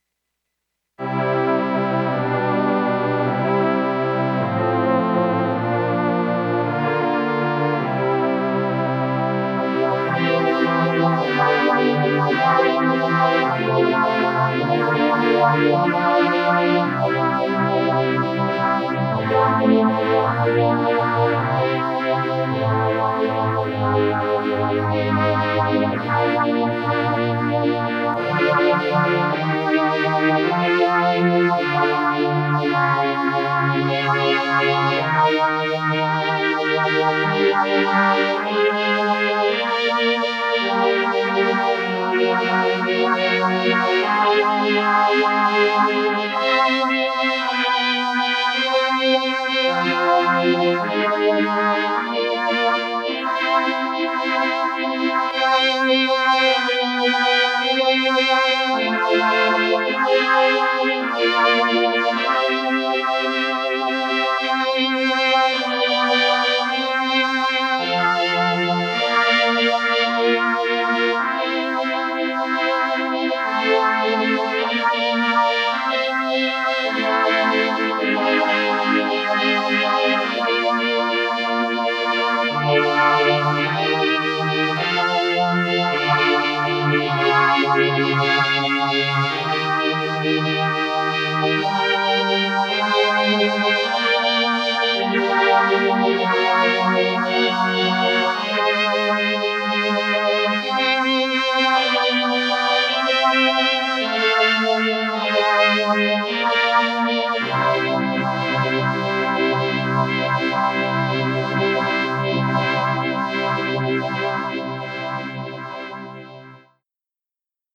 Double 4Stage "Vintage" String Machine
First 10 seconds are dry. A 2 oscillator Kawai K5 additive synth string patch effected by two series 4Stage stereo phasers (two series instances of the ChaosPhaser plugin). Each phaser instance has different LFO Rate, Random, Chaos, etc. Most old string machines such as Solina used multiple LFO chorus units rather than phasers, but chorus and phasers have some overlap.
This patch tries for the same "overkill phaser churn" but because the LFOs do not run steady, IMO it is not as repetitive or boring as were instruments such as Solina which would repeat exactly the same churning pattern forever.
CPD_Double4StageStringMachine.mp3